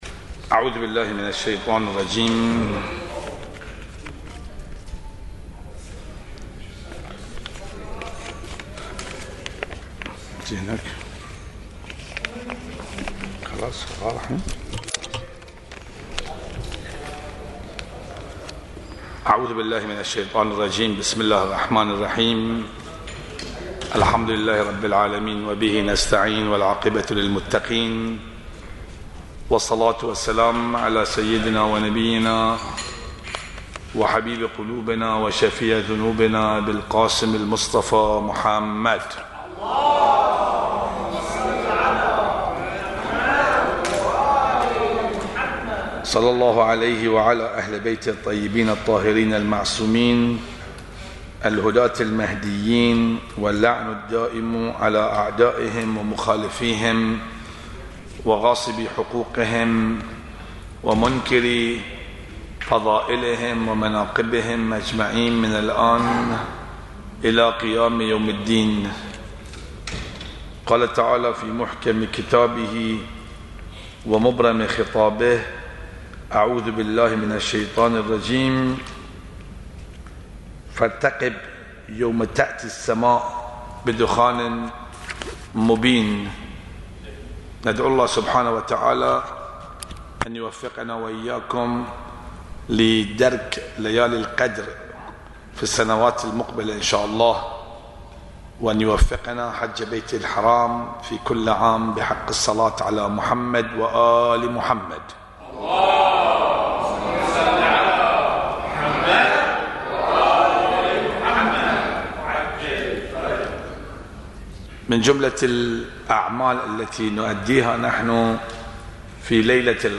المحاضرة 16: لماذا سورة الدخان في ليالي القدر | Alkawthar